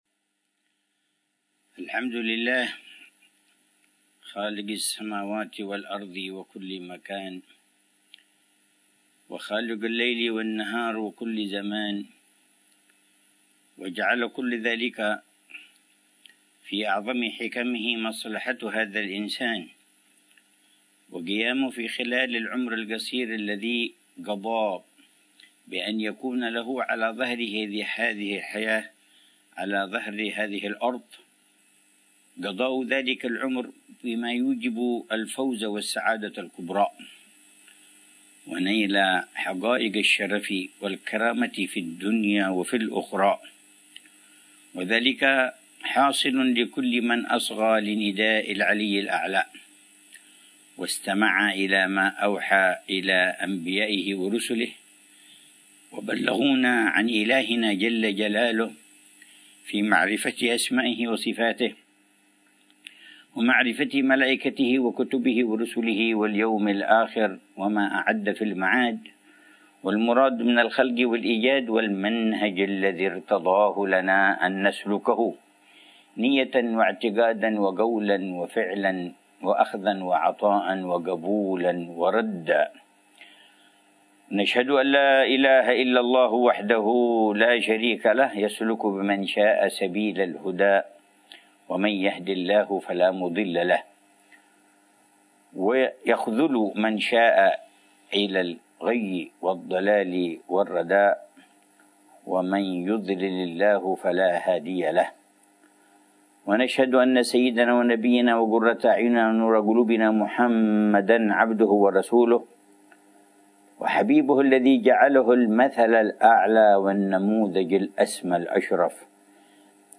محاضرة العلامة الحبيب عمر بن محمد بن حفيظ عن استقبال رمضان، عصر يوم الأحد 24 شعبان 1446هـ ، بعنوان: